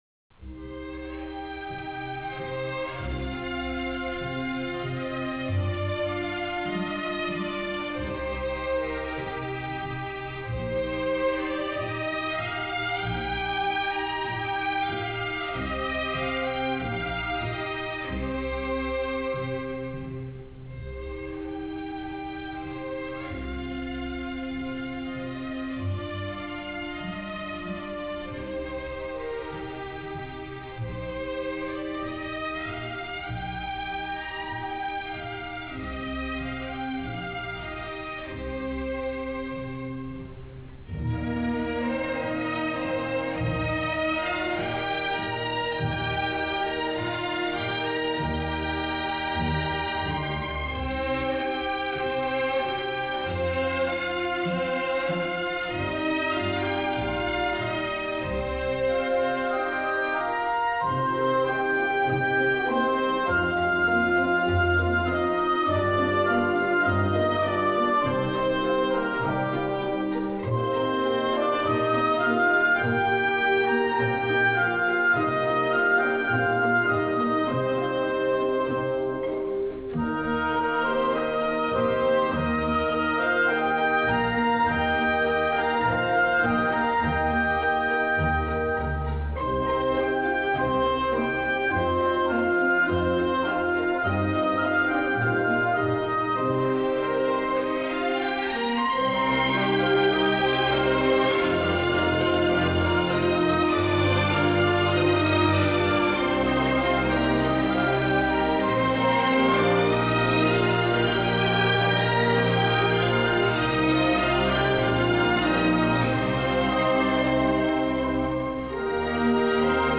Music On Hold Collection
Professional audio files for your business phone system
Standard Queue Message